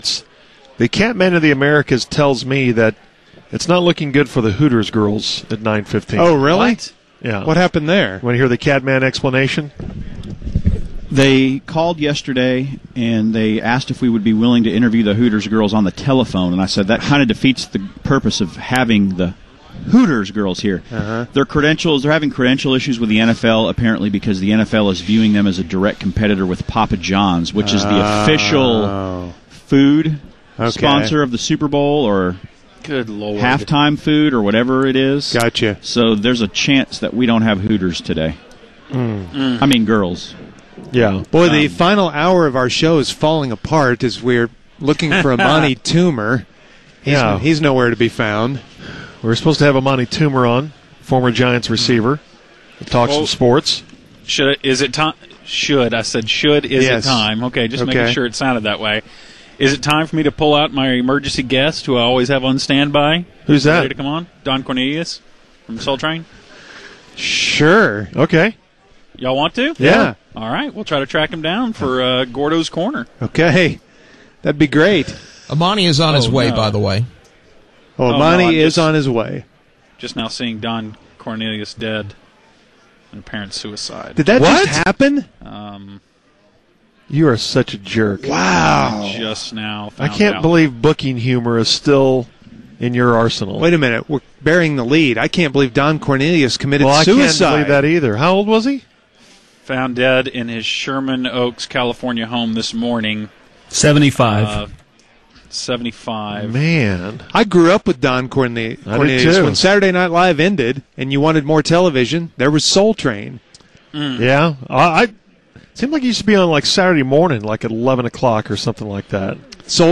Amani Toomer Interview - The UnTicket